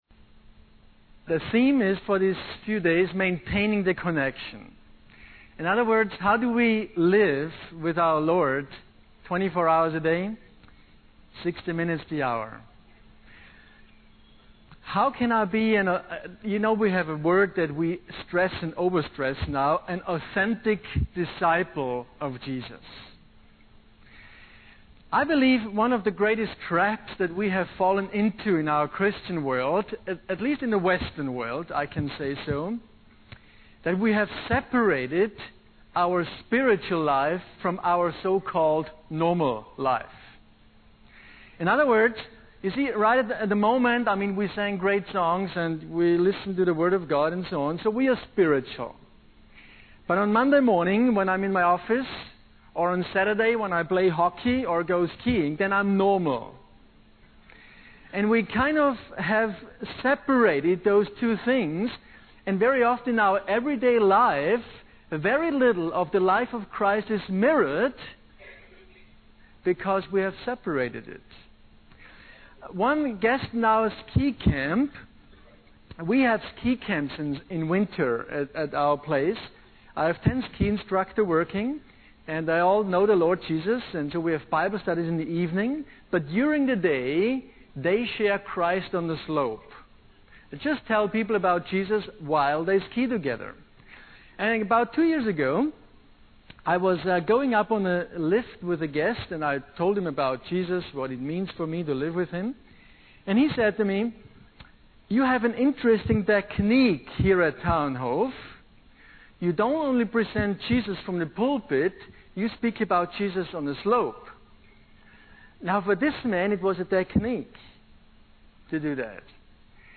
In this sermon, the speaker emphasizes the importance of maintaining a connection with God throughout our daily lives. He highlights the trap of separating our spiritual life from our normal life and reminds us that everything we have is a gift from God. The speaker also addresses the belief that we have a right to physical health and prosperity, cautioning against the health, wealth, and prosperity movement.